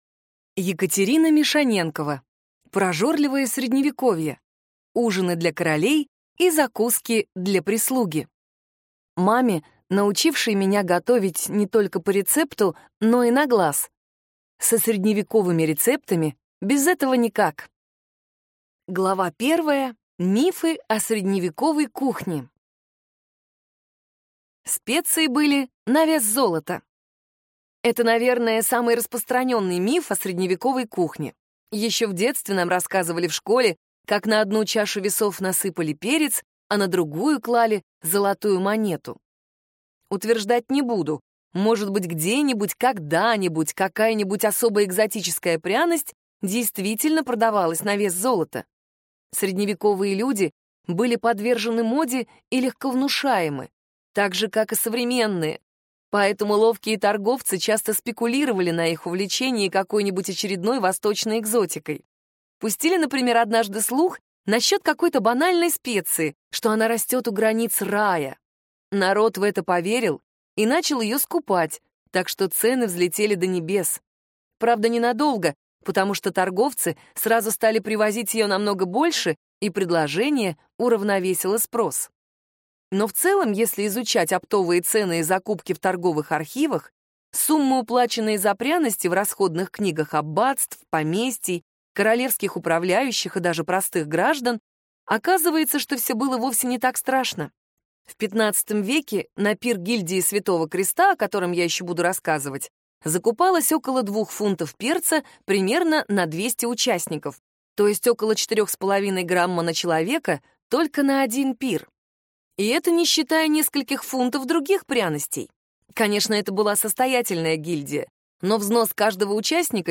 Аудиокнига Прожорливое Средневековье. Ужины для королей и закуски для прислуги | Библиотека аудиокниг
Прослушать и бесплатно скачать фрагмент аудиокниги